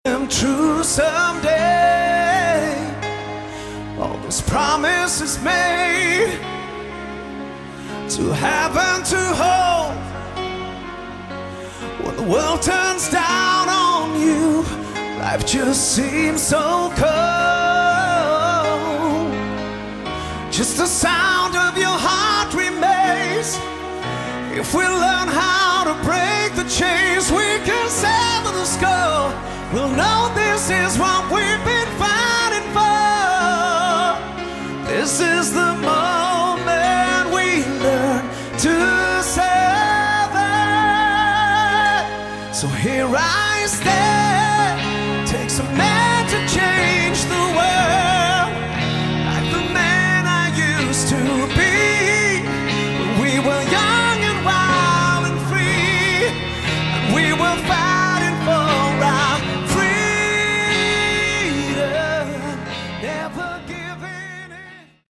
Category: Melodic Rock
lead vocals
guitar
keyboards
drums
bass